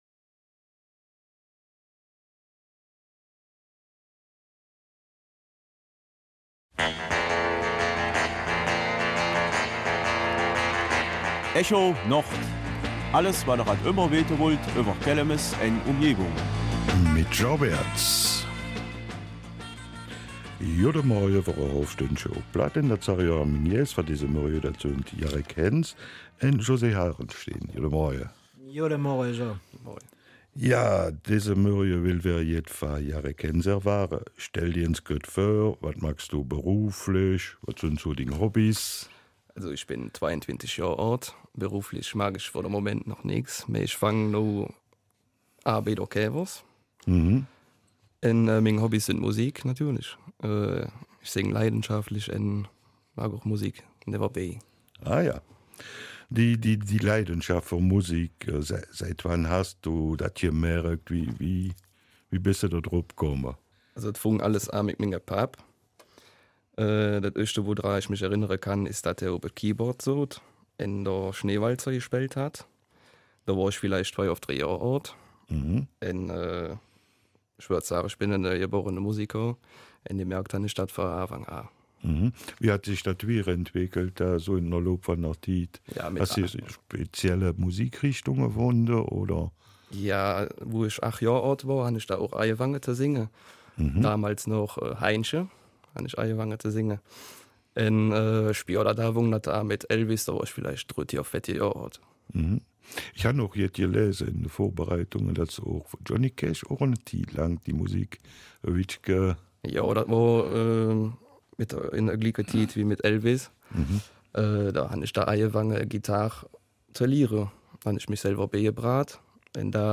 Studiogästen